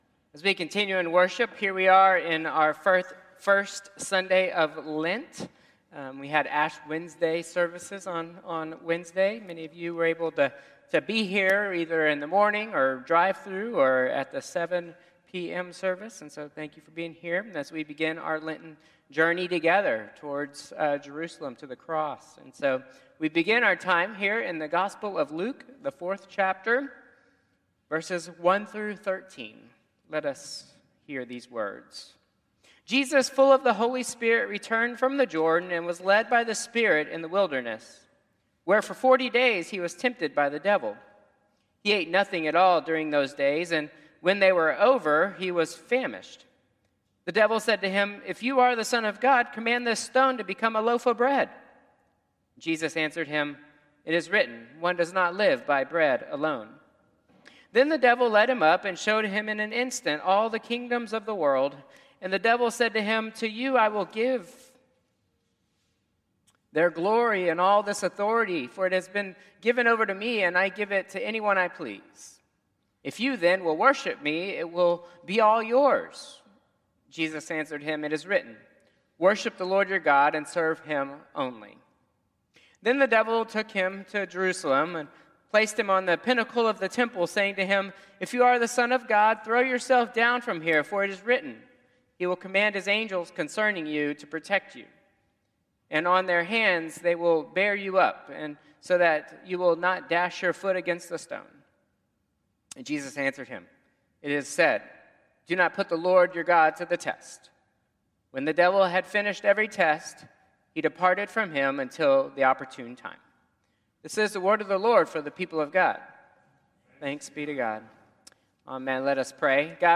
Traditional Service 3/9/2025